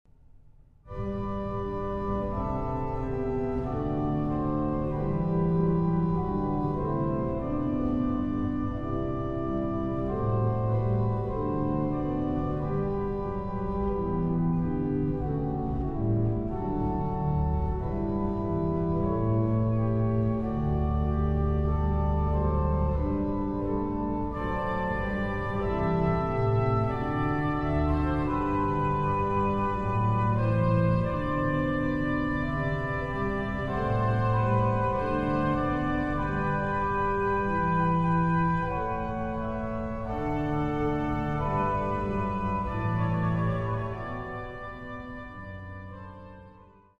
Knipscheer-orgel